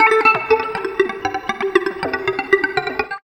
78 GTR 2  -R.wav